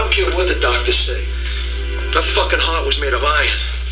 Iron - (Chazz From Diabolique, Her Heart speech) 84.7KB